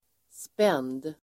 Uttal: [spen:d]